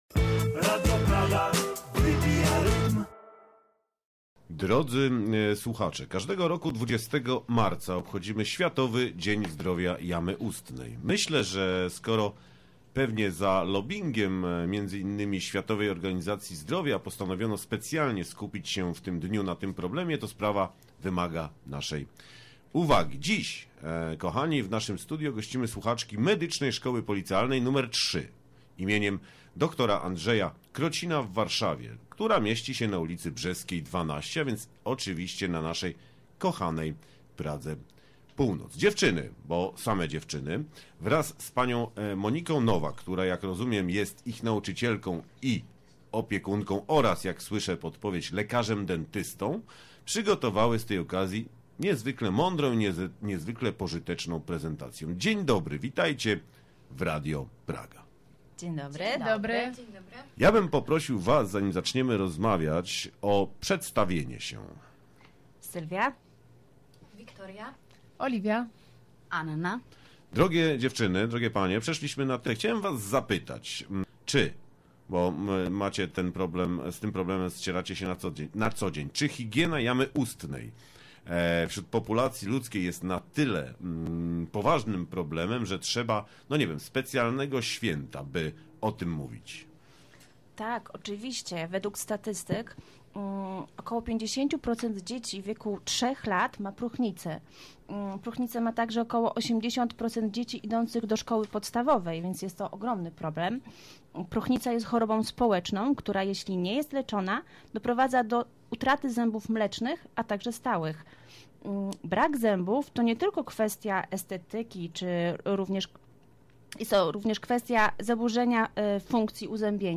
Słuchaczki przygotowujące się do zawodu higienistki stomatologicznej odwiedziły nasze studio i w merytorycznej prelekcji opowiedziały o zasadach higieny naszych zębów.